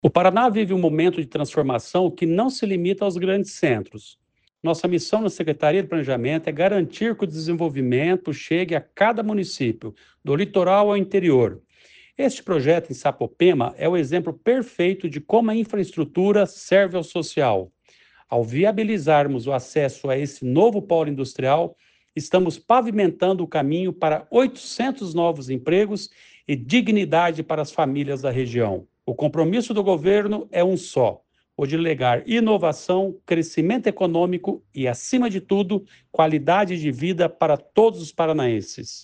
Sonora do secretário do Planejamento, Ulisses Maia, sobre a rodovia de concreto em Sapopema